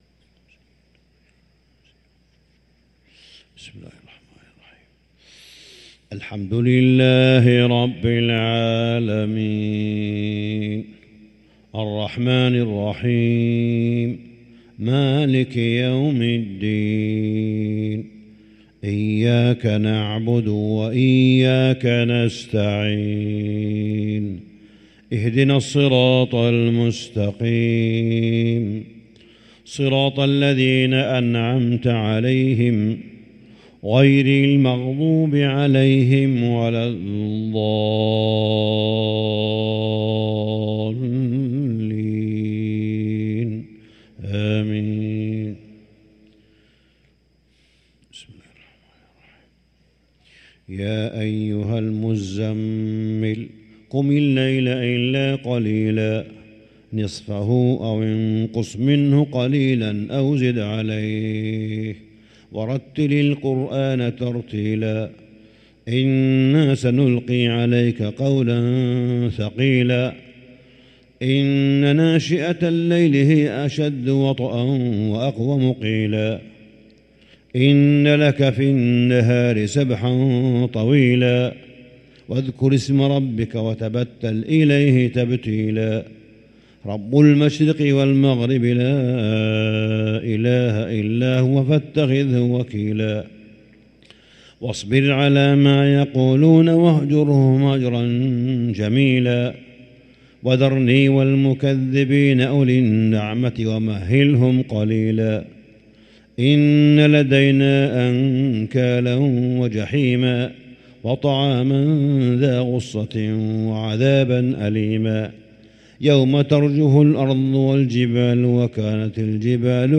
صلاة الفجر للقارئ صالح بن حميد 19 رمضان 1444 هـ
تِلَاوَات الْحَرَمَيْن .